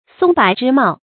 松柏之茂 注音： ㄙㄨㄙ ㄅㄞˇ ㄓㄧ ㄇㄠˋ 讀音讀法： 意思解釋： 比喻長青不衰。